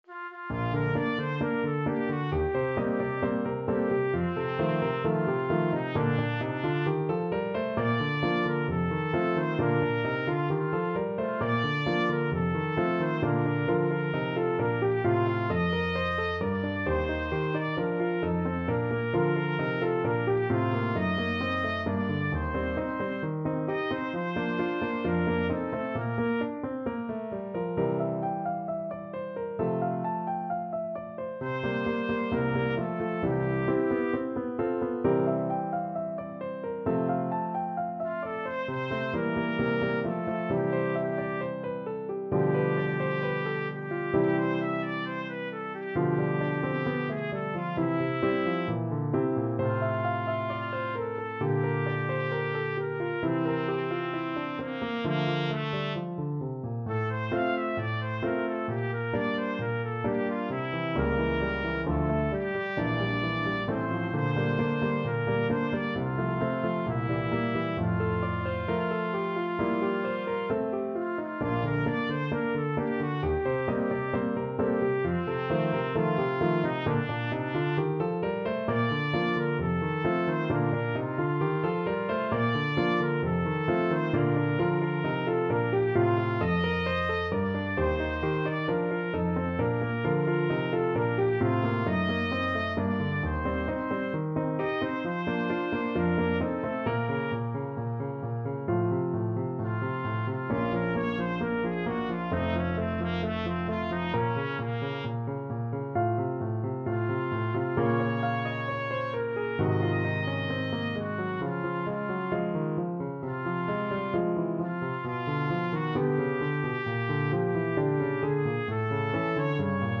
Classical Mozart, Wolfgang Amadeus Mi tradi quell'alma ingrata from Don Giovanni Trumpet version
Bb major (Sounding Pitch) C major (Trumpet in Bb) (View more Bb major Music for Trumpet )
=132 Allegro assai (View more music marked Allegro)
2/2 (View more 2/2 Music)
Classical (View more Classical Trumpet Music)